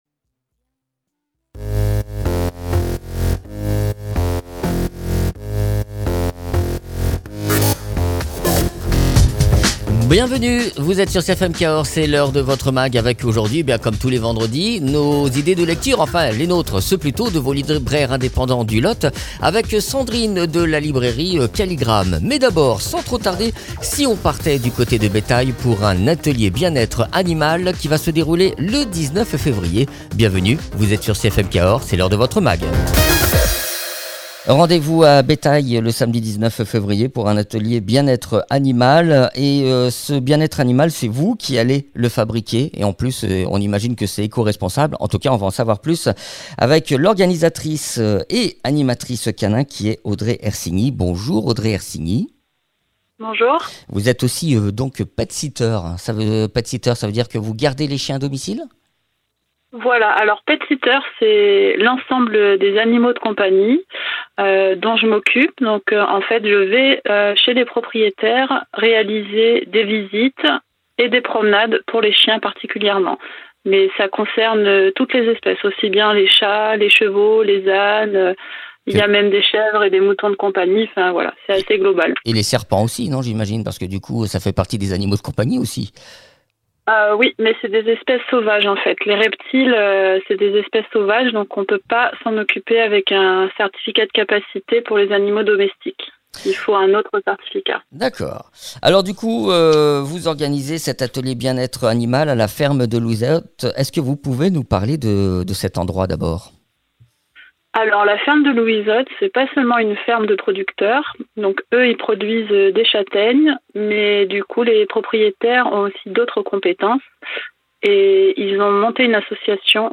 petsitter et animatrice canin.
libraire.